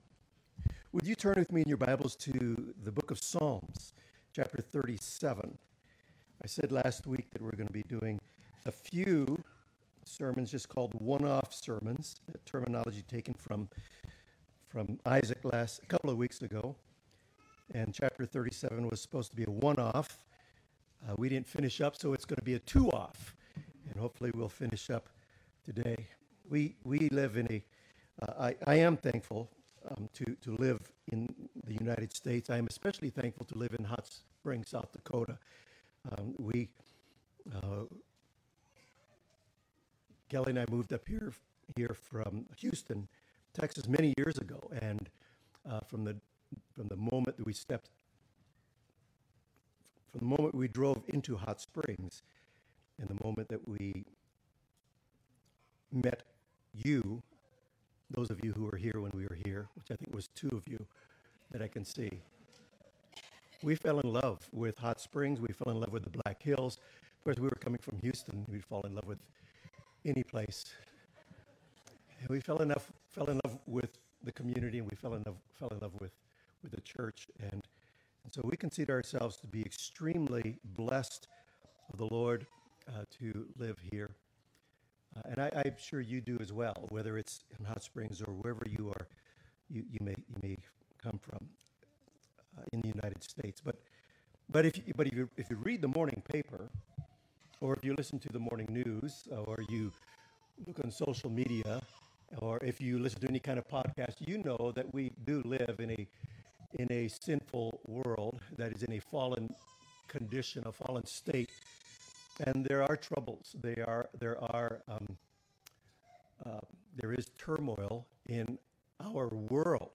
One Off Sermons
Service Type: Morning Service